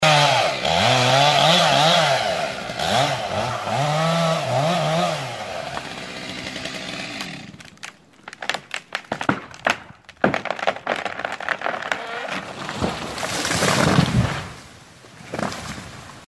Звуки деревьев